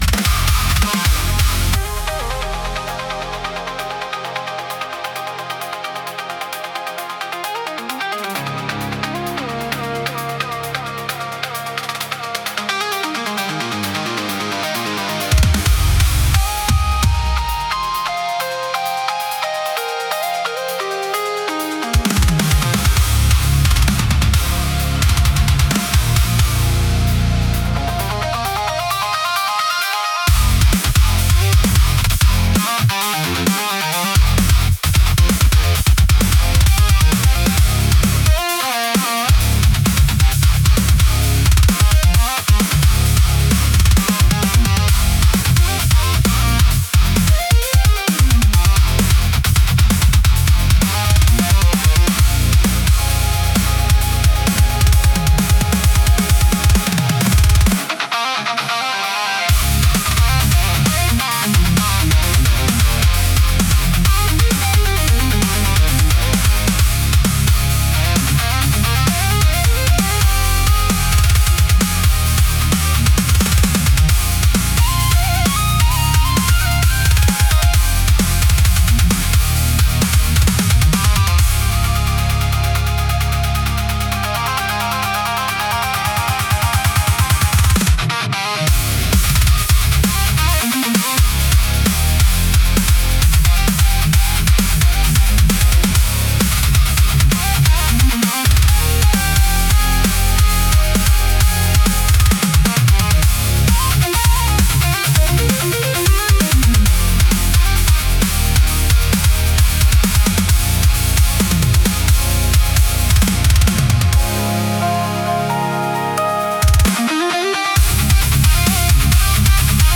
イメージ：インスト,グリッチロック,メタル,ダーク,パワーロック
インストゥルメンタル（instrumental）